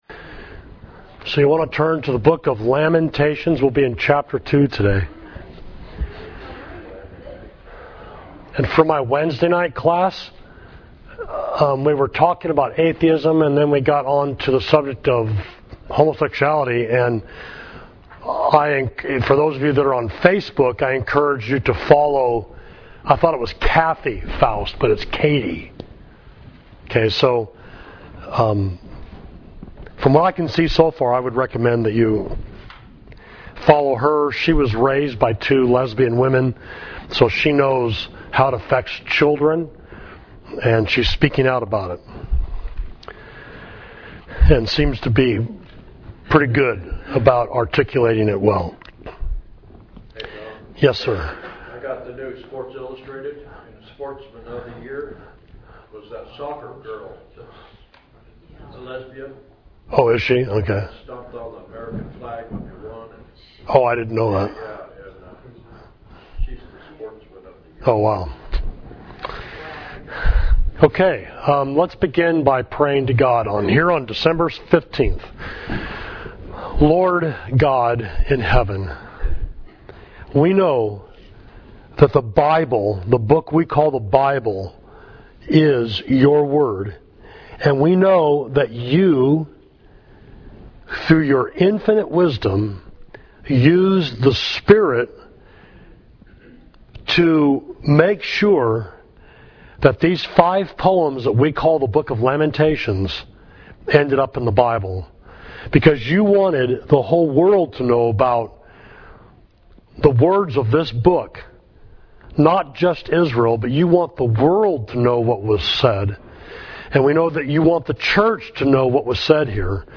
Class: God’s Wrath on Jerusalem, Lamentations 2